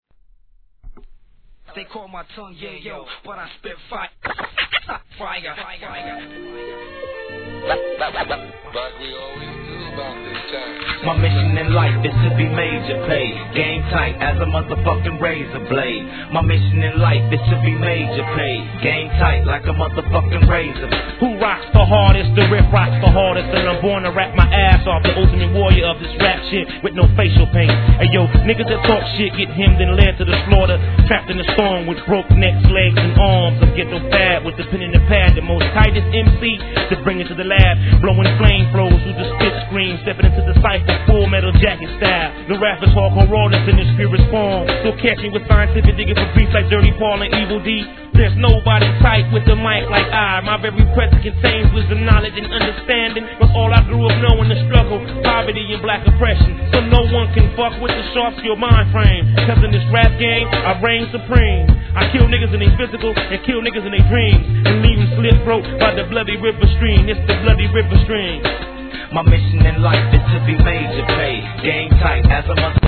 HIP HOP/R&B
ストリングスのヤバイサウンドに癖のあるフロウで聴かせる'01年のA級アンダーグランド!!